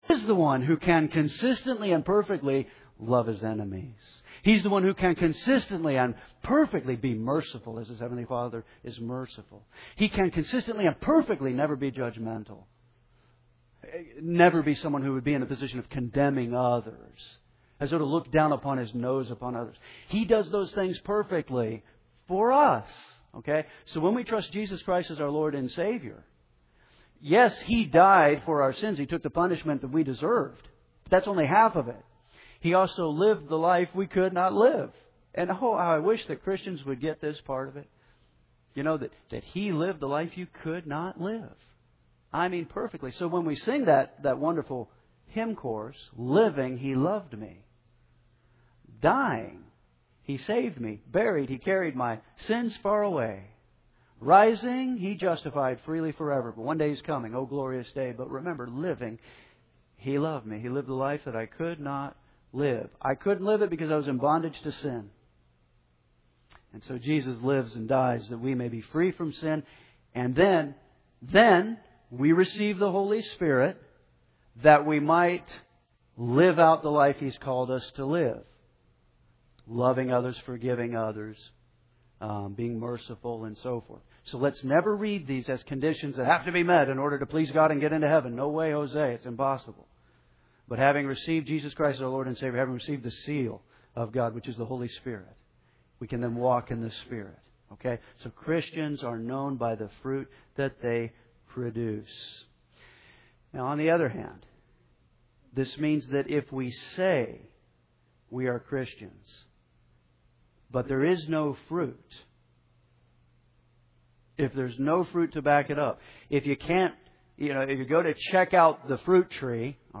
Henderson’s First Baptist Church, Henderson KY